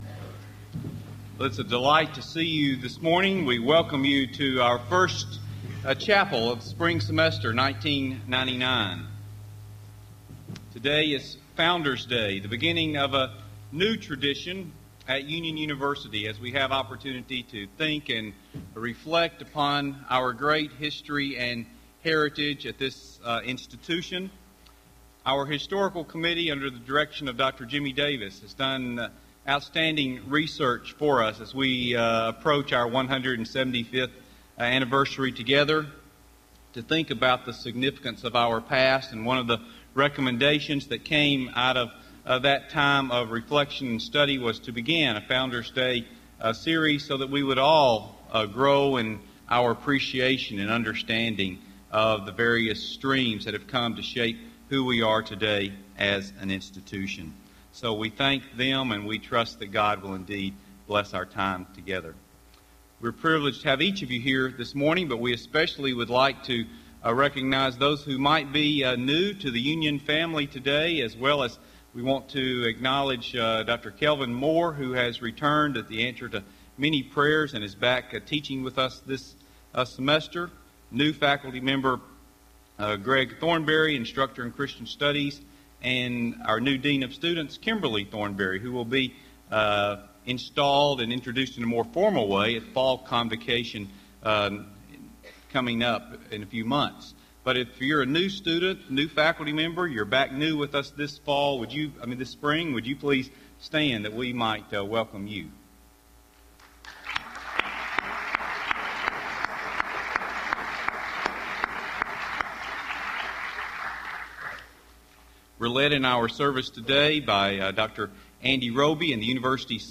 Founders' Day Chapel: Albert Mohler, Jr.
R. Albert Mohler, Jr , President, Southern Baptist Theological Seminary, Louisville, KY Address: Union University and the Southern Baptist Convention Recording Date: Feb 8, 1999, 10:00 a.m. Length: 38:06 Format(s): MP3 ;